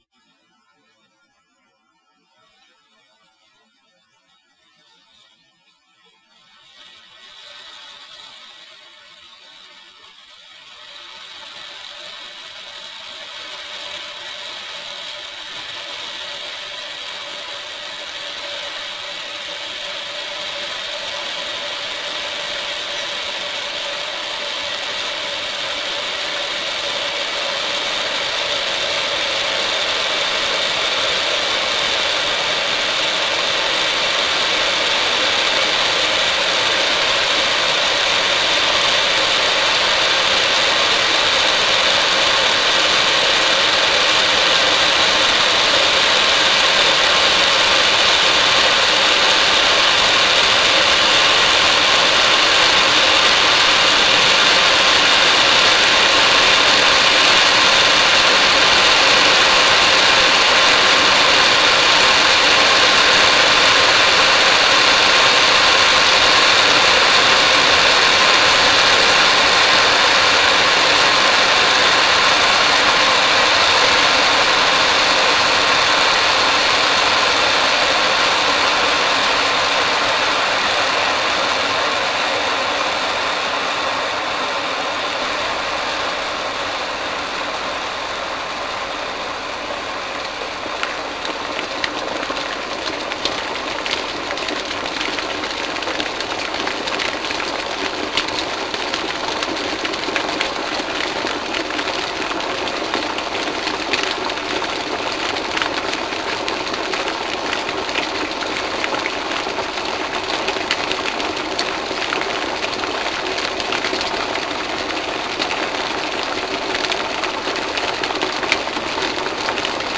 描述：金属锅中的沸水